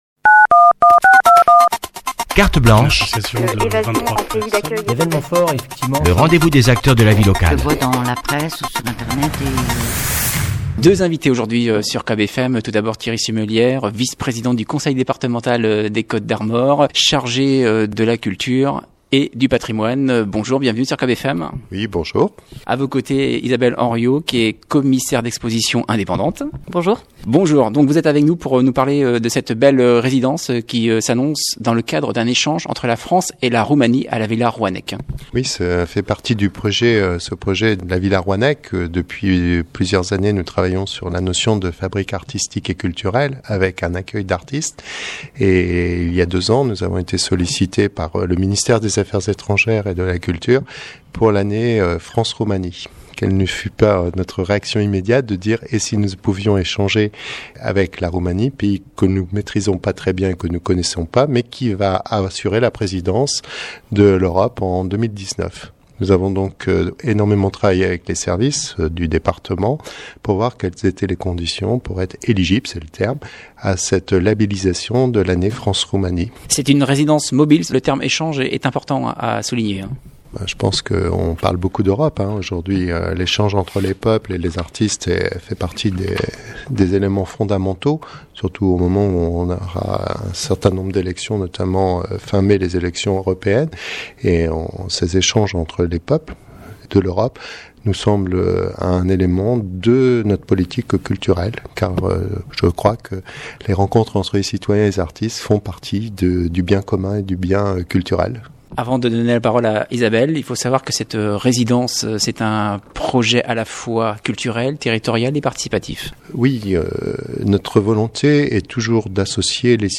villa à soiInvités de la rédaction ce lundi, Thierry Simelière, vice-président du Département chargé de la culture et du patrimoine